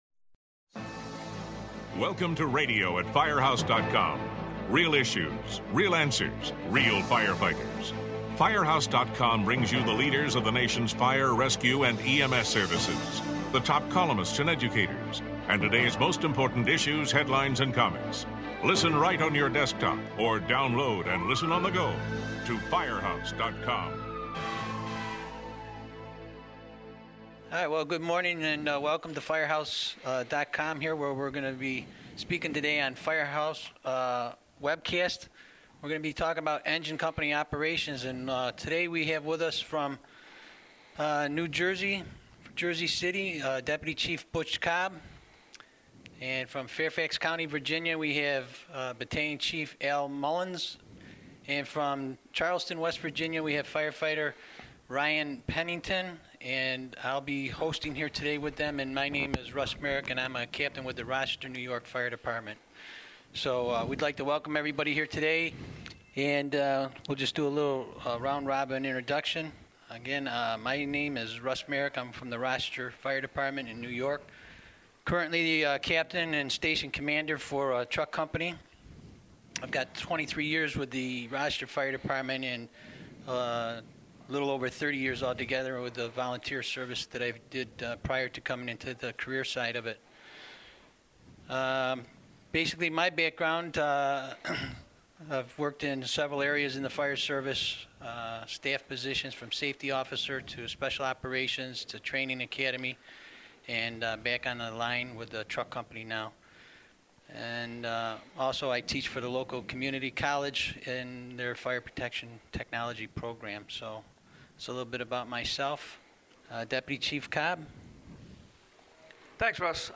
The group looks at what the priorities are for responses to the growing number of both vacant structures and the larger, “McMansion”-style homes that are popping up from urban to rural cities. Listen as each fire service veteran shares their experiences for succussful fireground operations.
This podcast was recorded at Firehouse Expo in July.